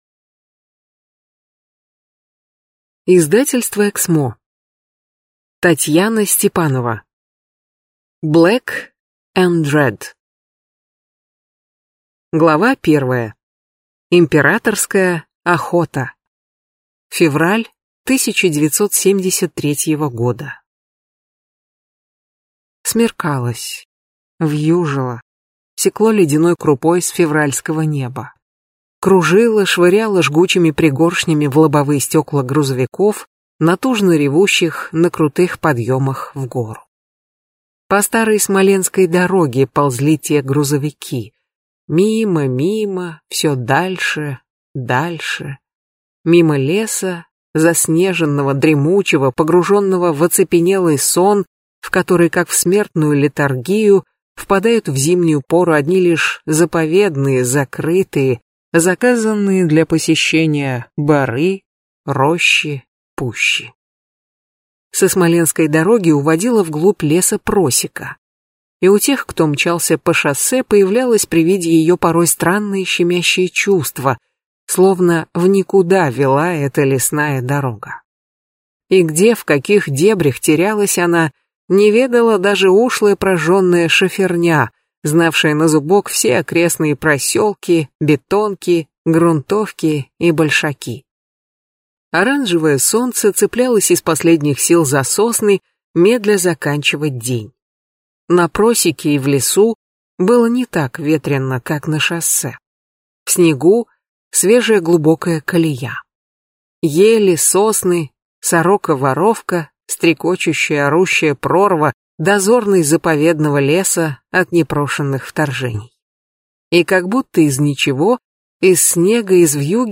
Аудиокнига Black & Red | Библиотека аудиокниг